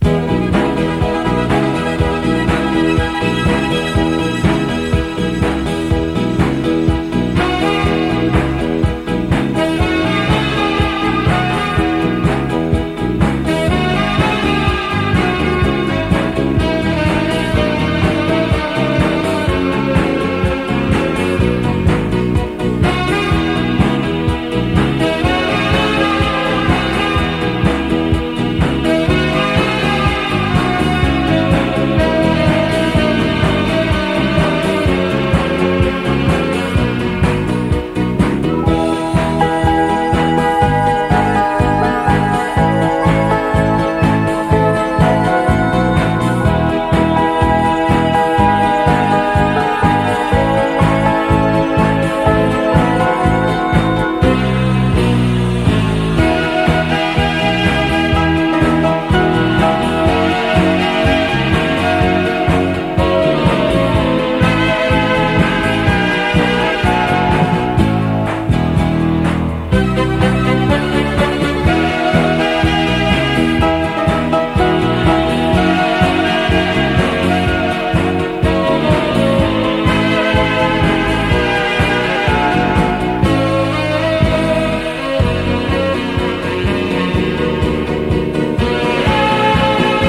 めくるめくストリングス・オーケストラ・サウンドとグッと太いリズム。